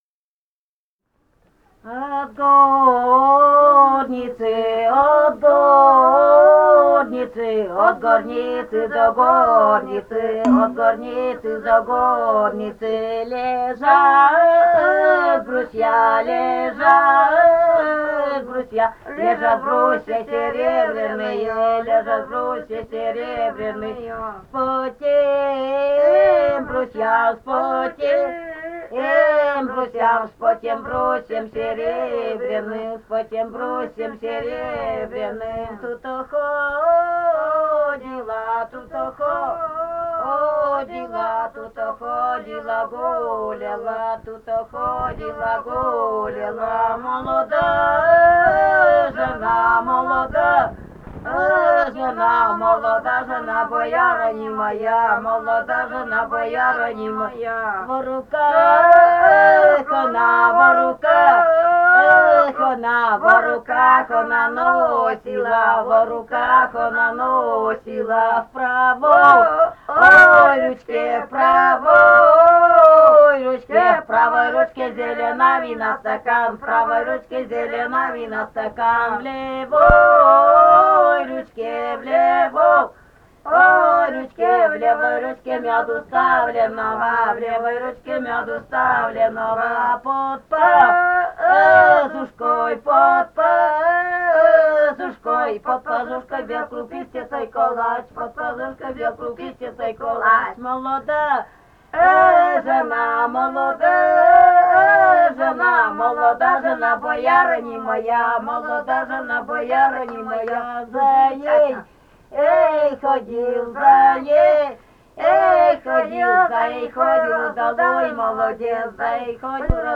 полевые материалы
«От горницы до горницы» (свадебная).
Пермский край, д. Подвигаловка Кунгурского района, 1968 г. И1079-09